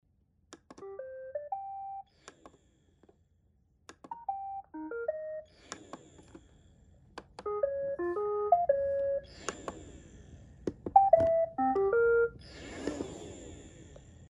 Звуки робота-пылесоса